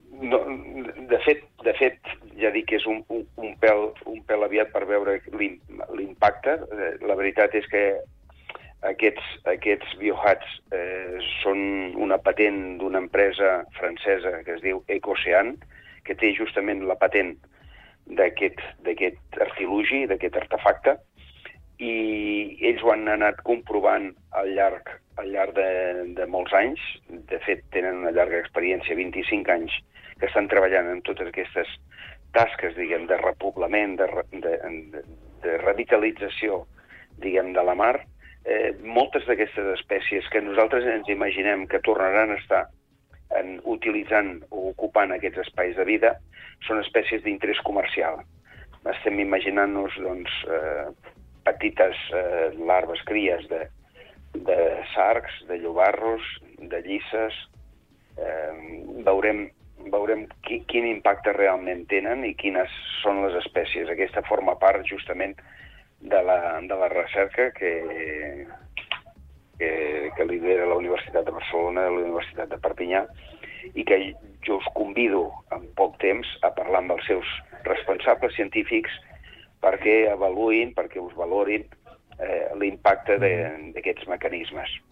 Tot això ja porta l’Ajuntament a pesar i preveure aquest futur a mitjà termini per adaptar el poble a aquest creixement. Maurici Jiménez, alcalde de Castell d’Aro, Platja d’Aro i s’Agaró, explica a DE CAP A CAP l’actualitat del municipi i el seu futur.